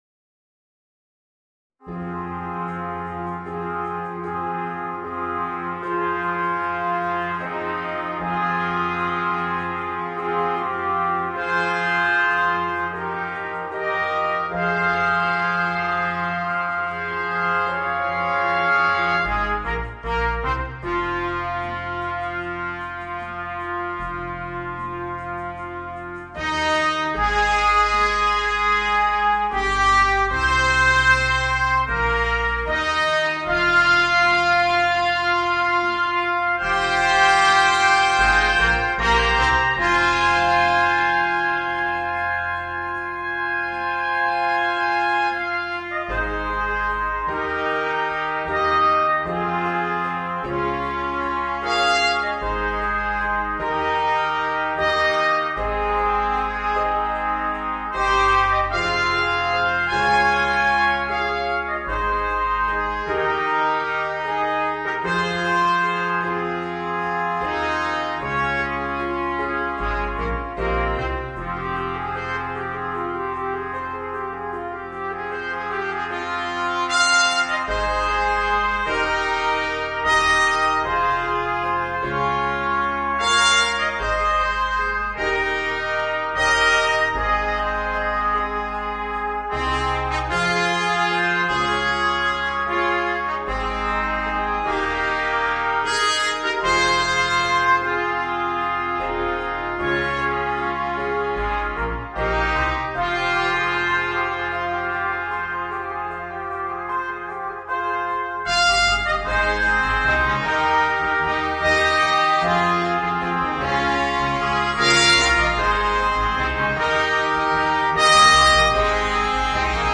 Voicing: 4 Trumpets